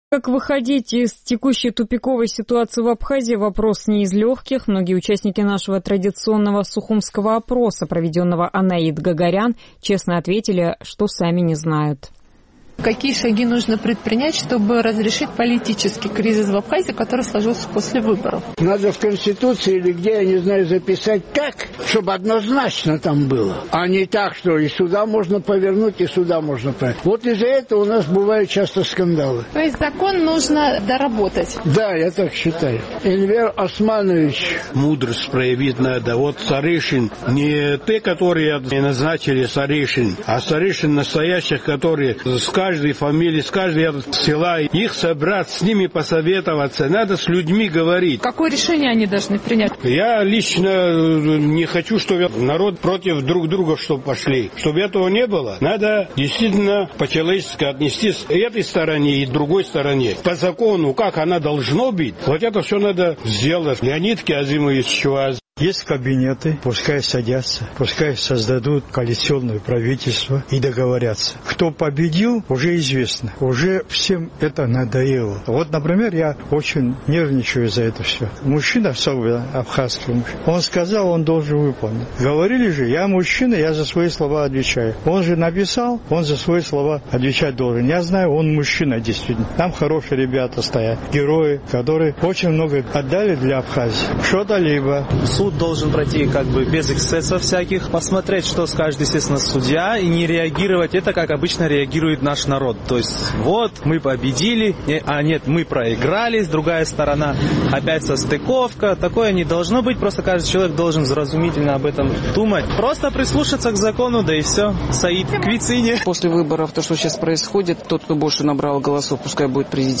Как выходить из текущей тупиковой ситуации в Абхазии – вопрос не из легких. Многие участники нашего традиционного сухумского опроса честно отвечают, что не знают.